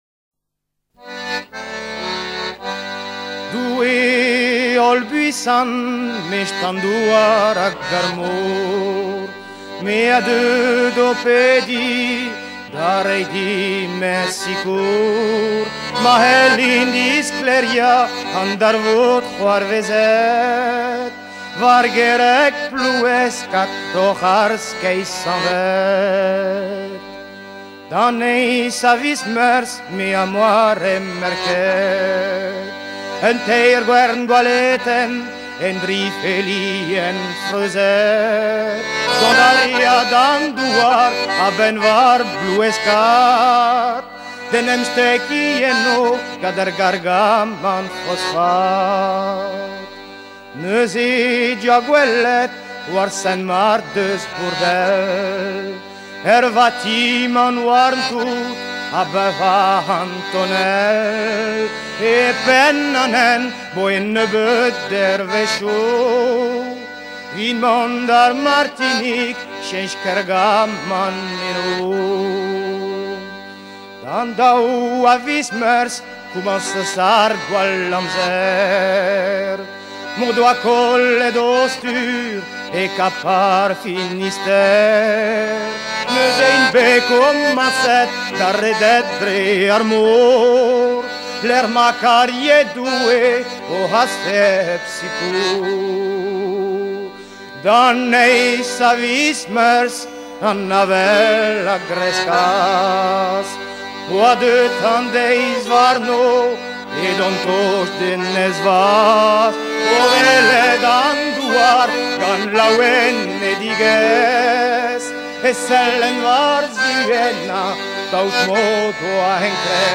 La gwerz évoque un naufrage qui eu lieu en 1901.
Genre strophique